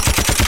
Machine Gun.wav